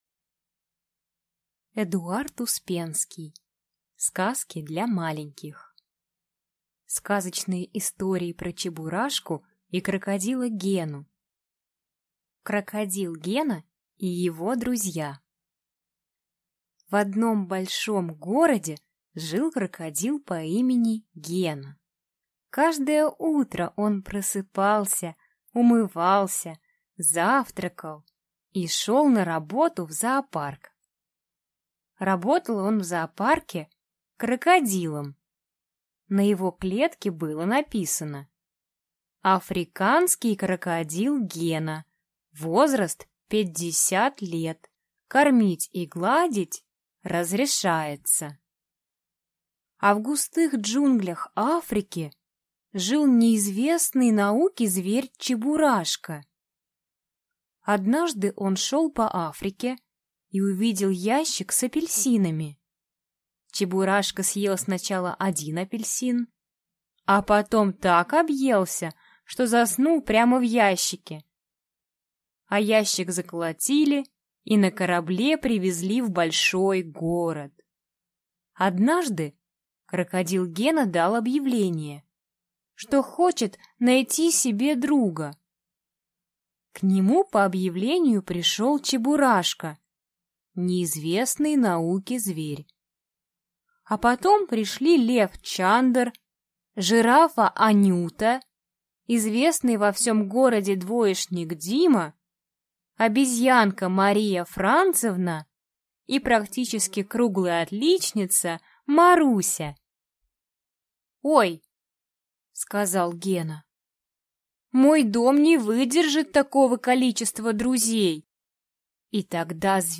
Аудиокнига Сказки для маленьких | Библиотека аудиокниг